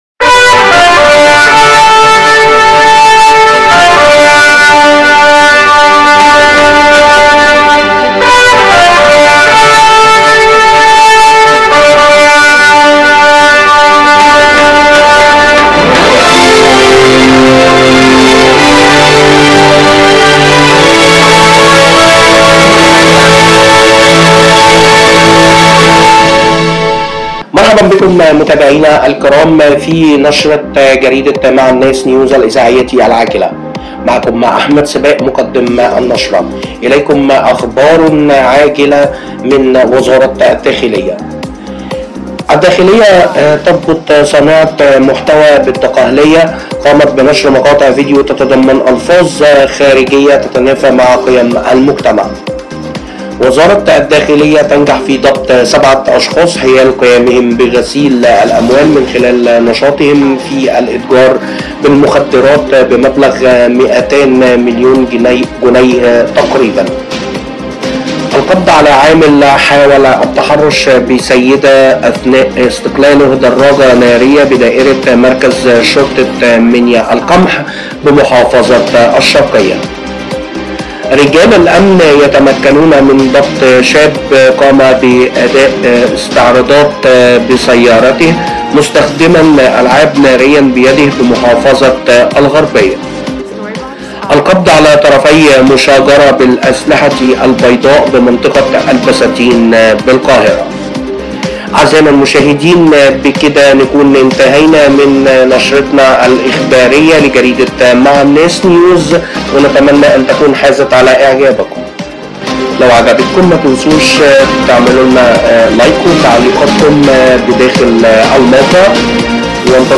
نشرة مع الناس نيوز الإذاعية و أخبار الحوادث المصرية